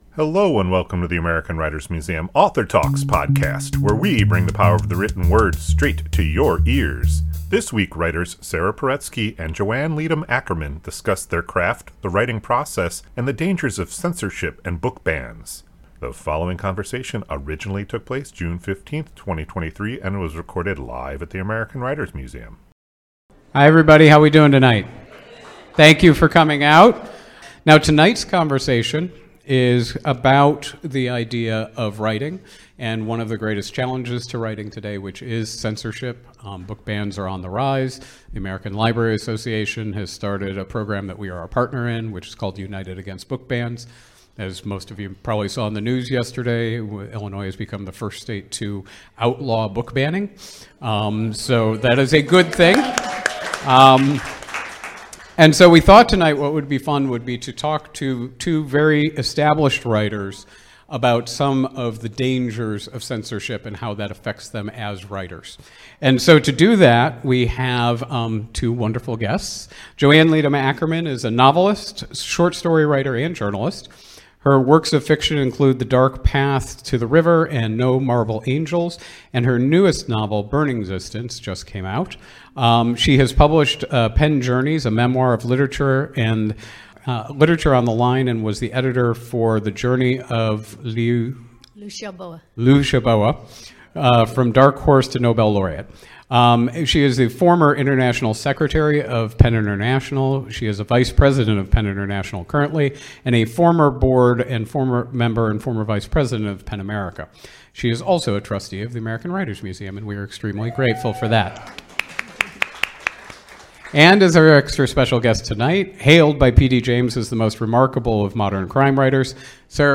This conversation originally took place June 15, 2023 and was recorded live at the American Writers Museum.